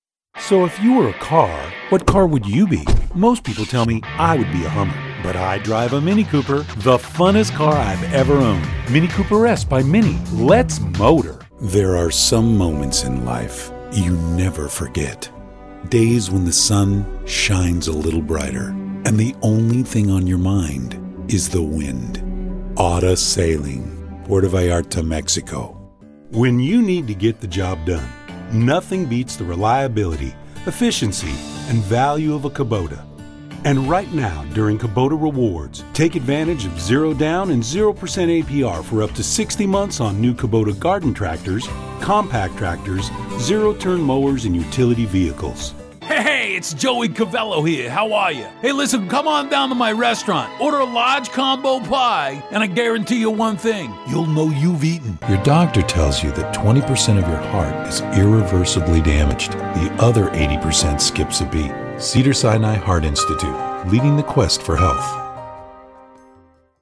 His signature sound is relaxed and conversational.
Baritone, warm, comforting, powerful, authoritive, sincere, authentic, fun, relaxed, conversational.
Sprechprobe: Werbung (Muttersprache):